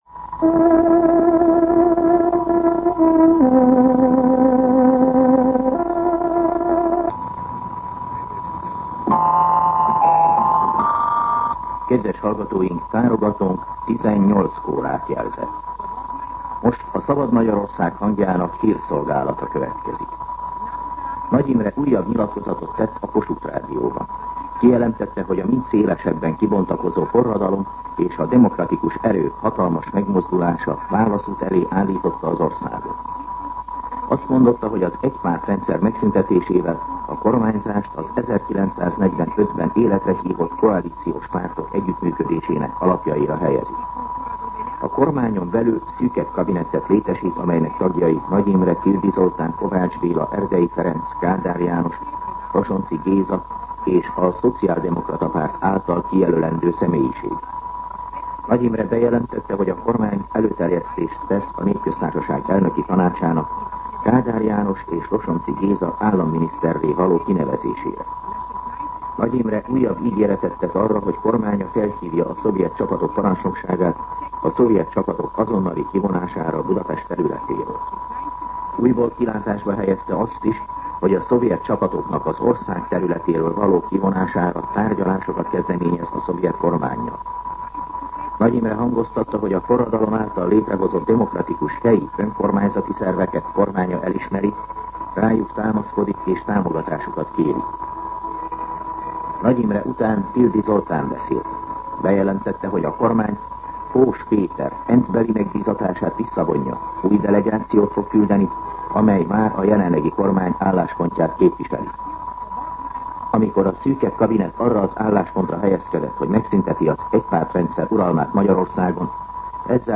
18:00 óra. Hírszolgálat